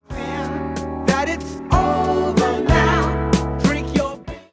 type II mu major used in the chorus